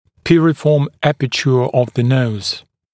[ˈpɪrɪfɔːm ‘æpətjuə əv ðə nəuz][ˈпирифо:м ‘эпэчйуэ ов зэ ноуз]носовое грушевидное отверстие